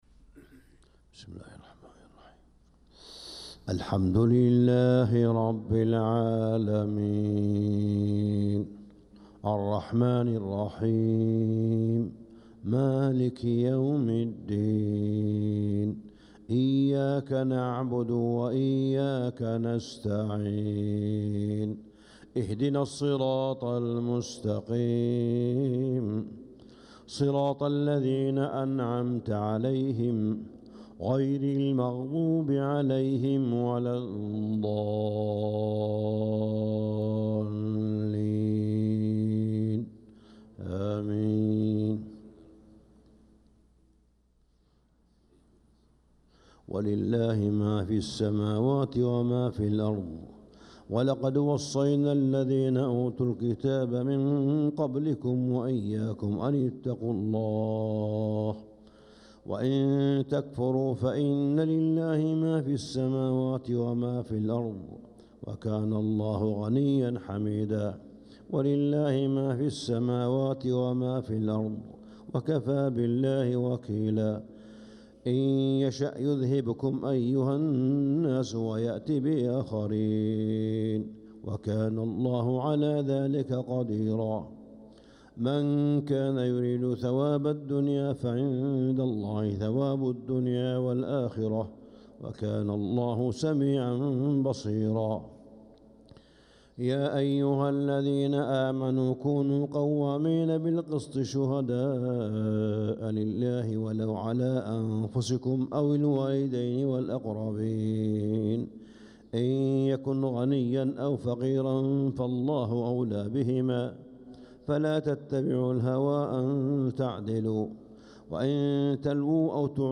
صلاة الفجر للقارئ صالح بن حميد 4 ربيع الأول 1446 هـ
تِلَاوَات الْحَرَمَيْن .